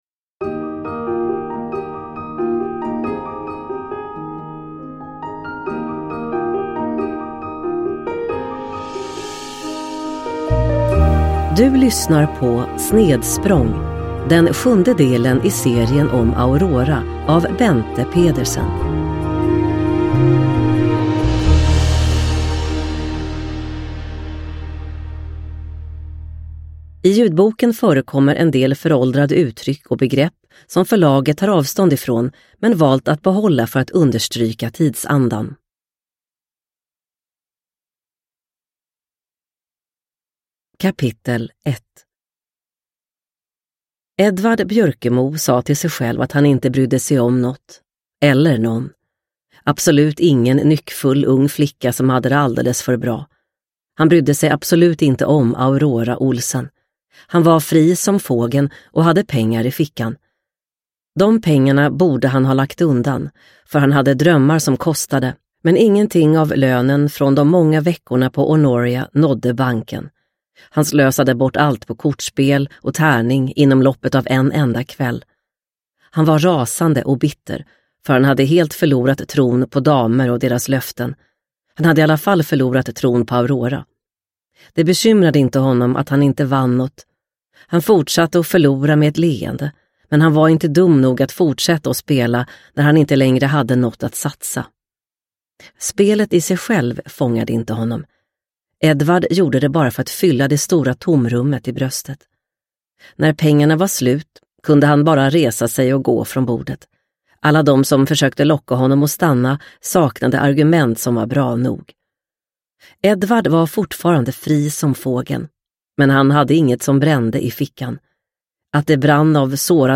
Snedsprång – Ljudbok – Laddas ner